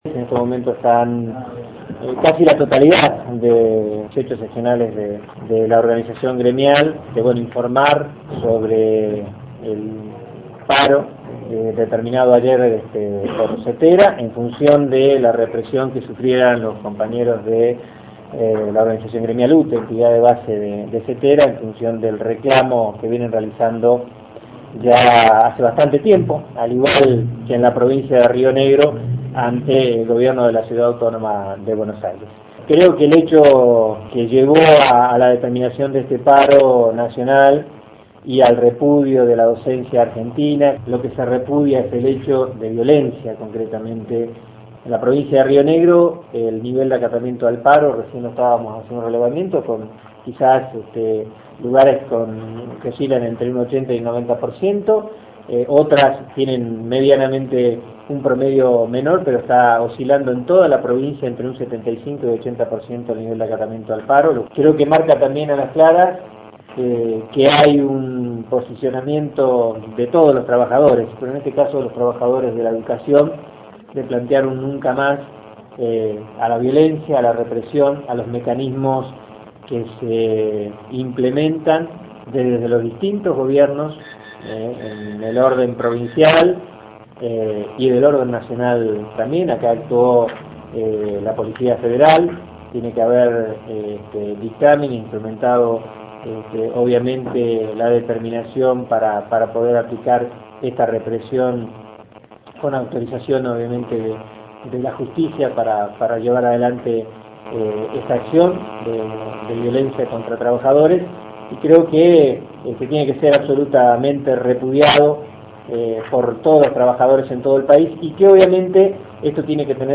media 22/05/09 Audio conferencia de prensa UnTER, 21 de octubre